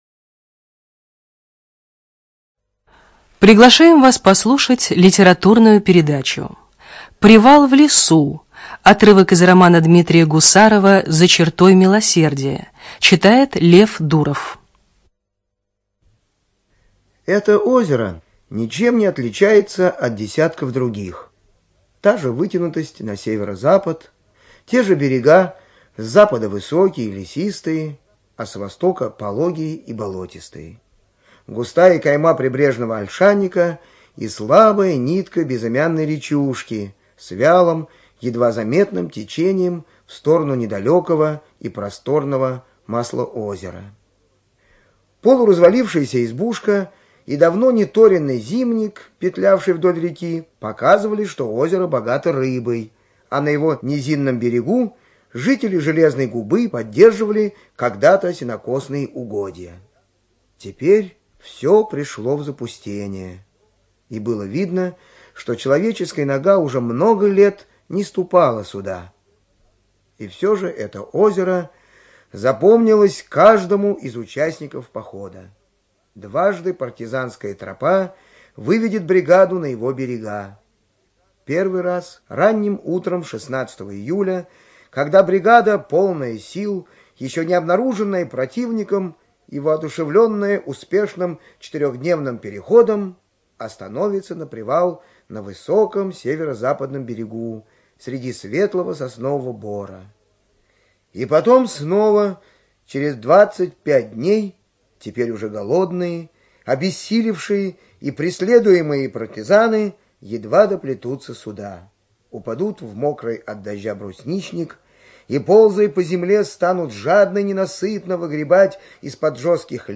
ЧитаетДуров Л.